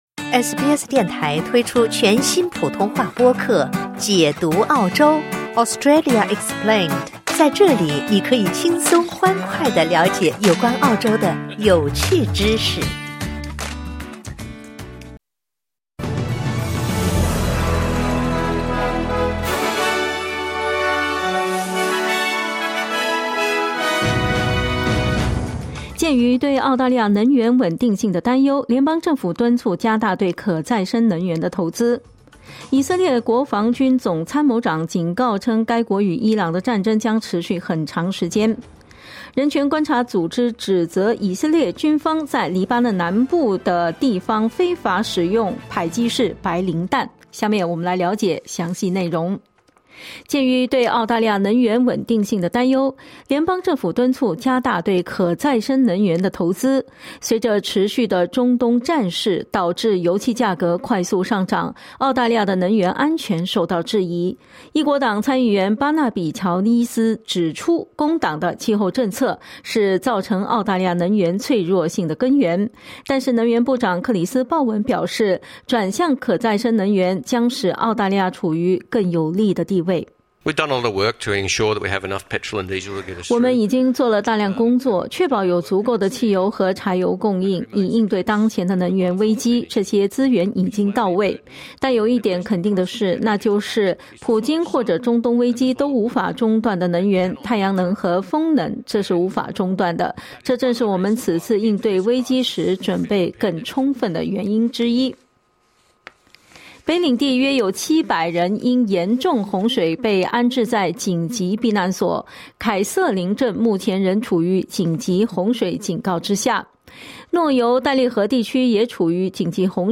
【SBS早新闻】以色列国防军总参谋长称与伊朗的战争将“持续很长时间”